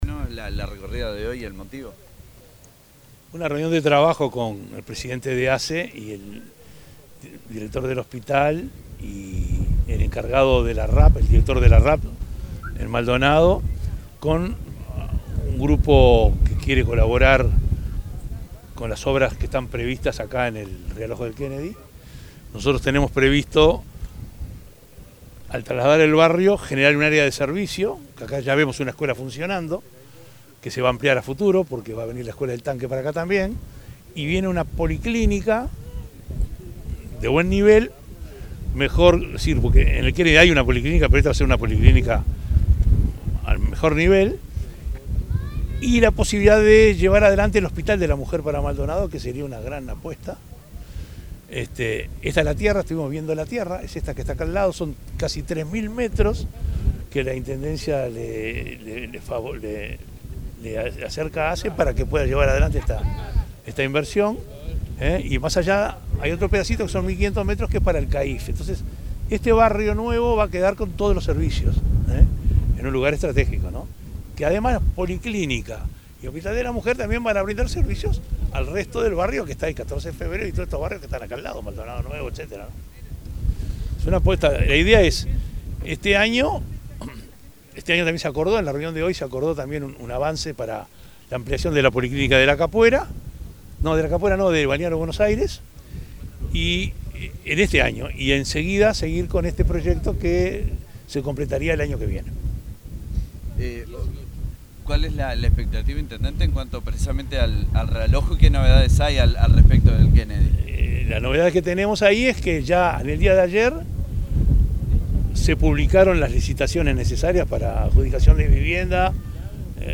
Declaraciones del intendente de Maldonado, Enrique Antía